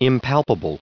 1595_impalpable.ogg